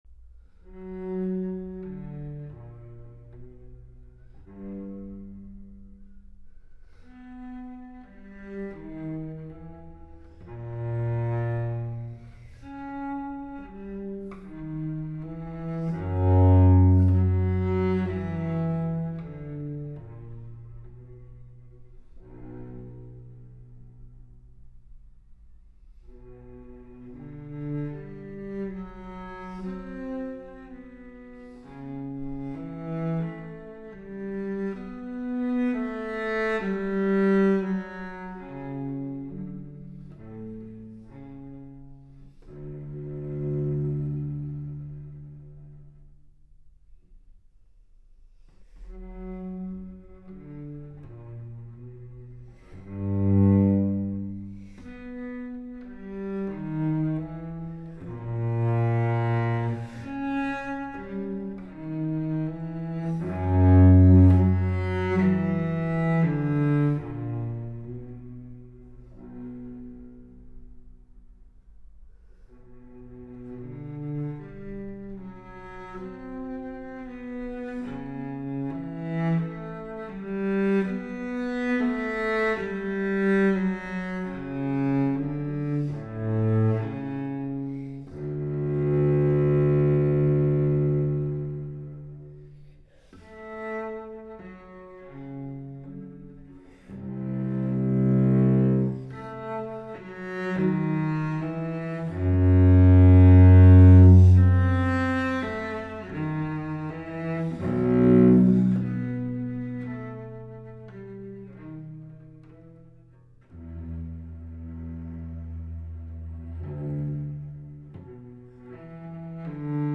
Трек размещён в разделе Зарубежная музыка / Классика.